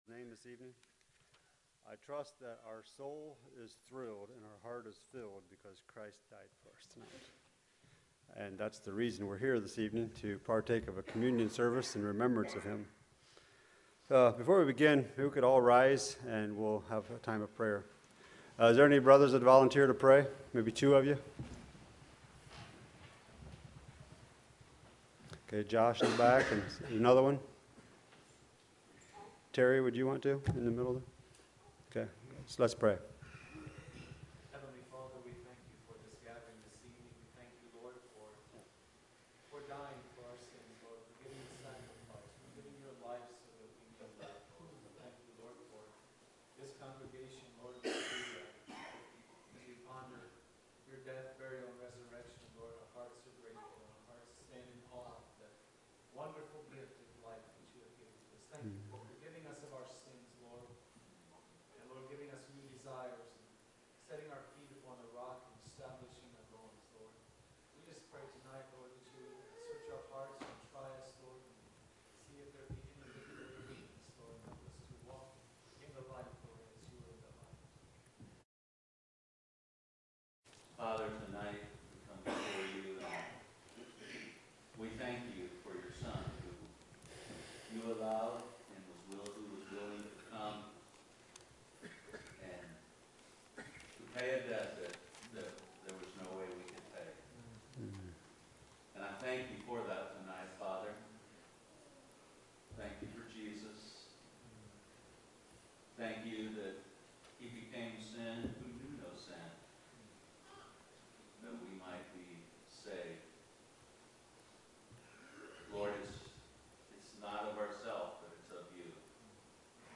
Communion Service
1460-Communion-Service-1.mp3